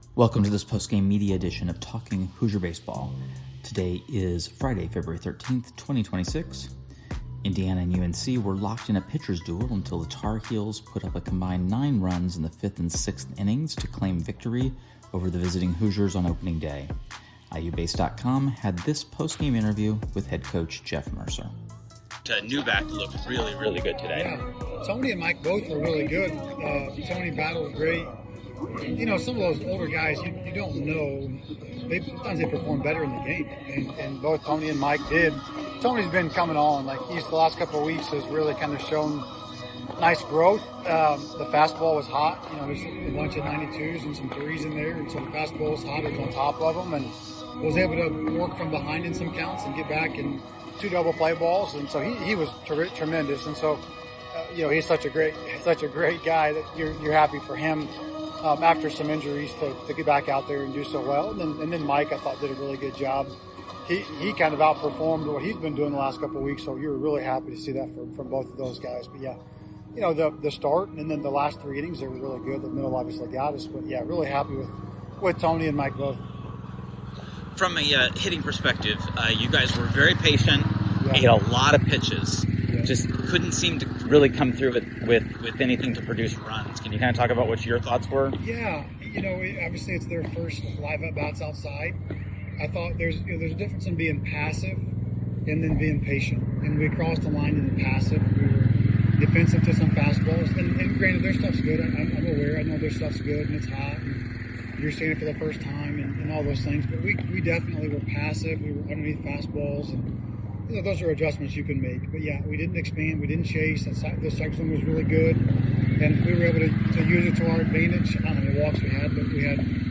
Postgame Media – Friday at North Carolina